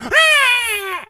pgs/Assets/Audio/Animal_Impersonations/monkey_hurt_scream_04.wav at master
monkey_hurt_scream_04.wav